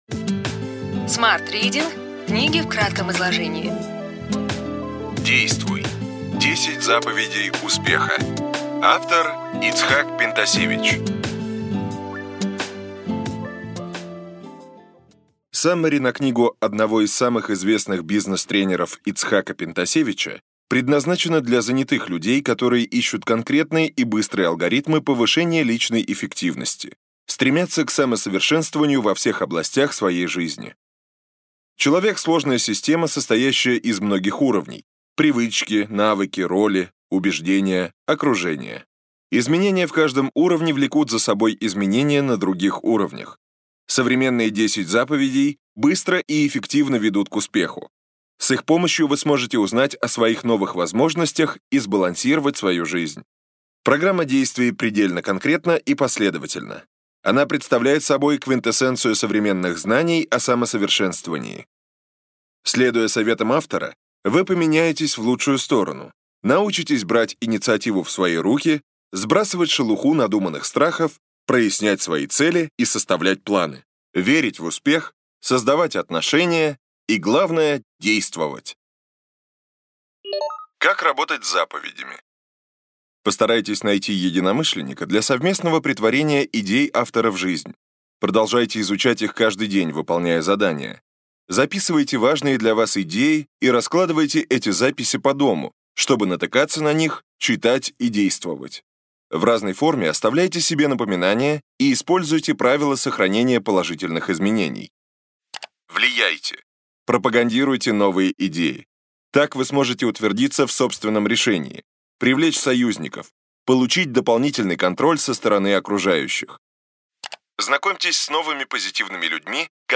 Аудиокнига Ключевые идеи книги: Действуй! 10 заповедей успеха.